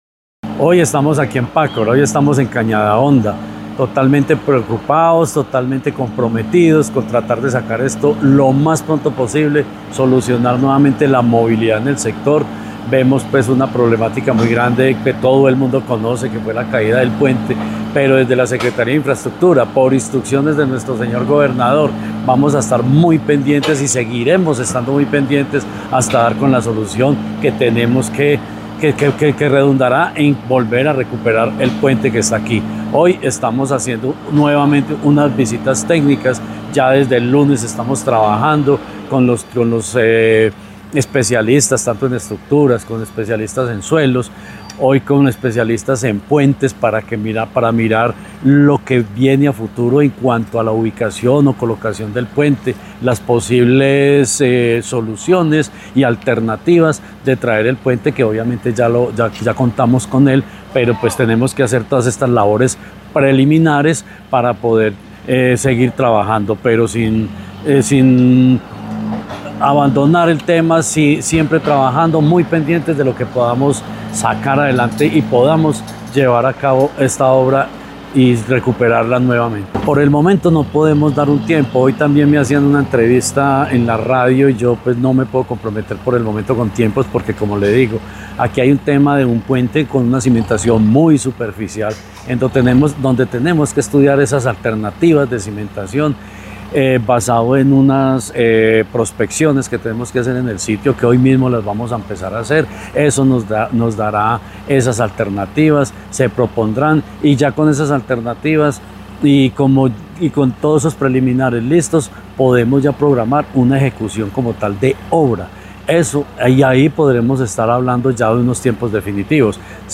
Secretario de Infraestructura, Jorge Ricardo Gutiérrez Cardona.